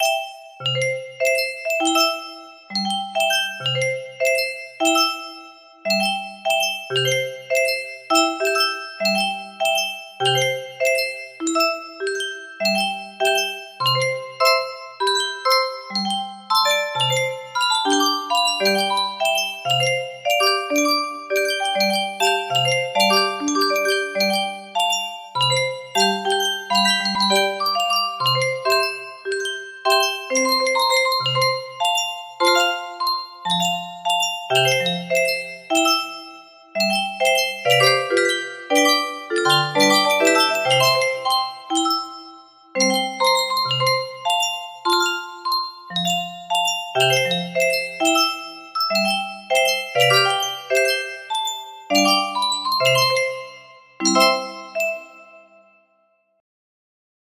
How To Convert Any Audio to Midi Files For Musicbox Creation. music box melody
Full range 60
(song Is An Arranged Version Of Brahms Lullaby BTW lol if anyones curious, just used it to fill the void while sharing these resources/toolls with you)